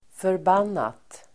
Uttal: [förb'an:at]